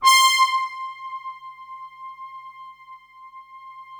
Index of /90_sSampleCDs/Best Service ProSamples vol.55 - Retro Sampler [AKAI] 1CD/Partition D/BRASS PAD